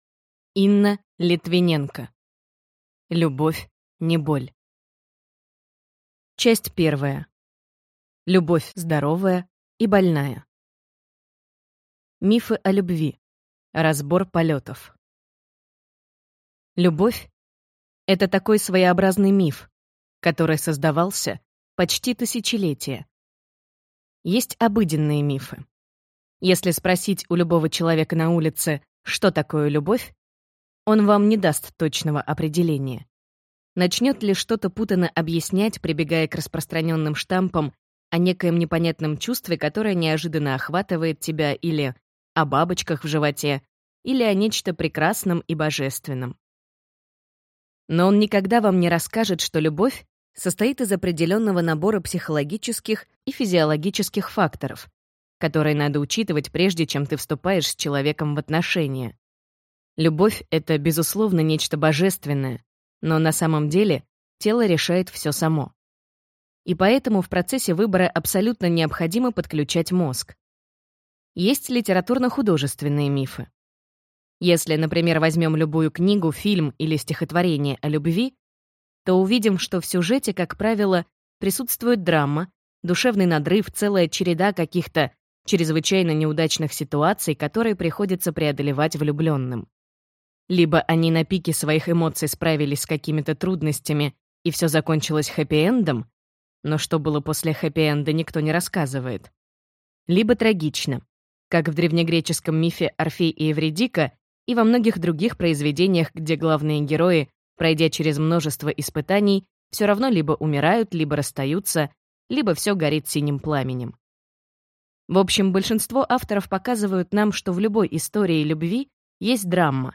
Аудиокнига Любовь – не боль. Здоровая любовь к себе, партнеру, родителям и детям | Библиотека аудиокниг